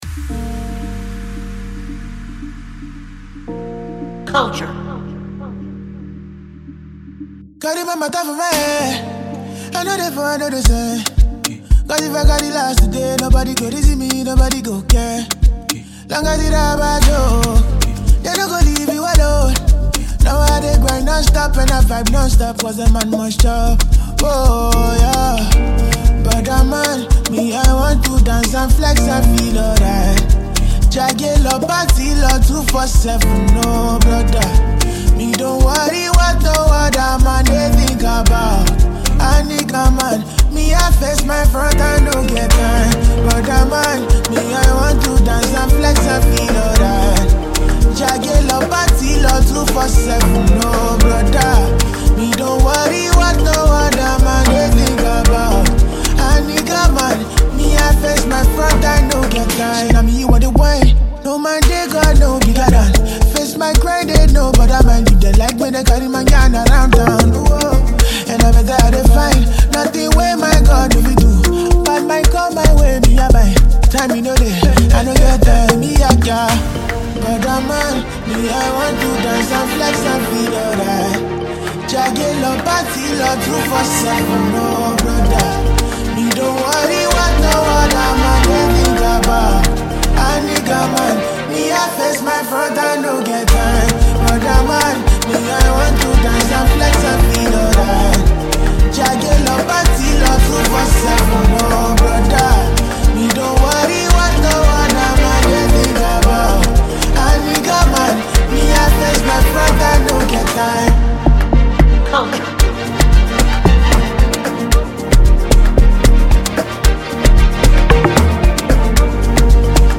Nigerian Afro Pop and R&B singer-songwriter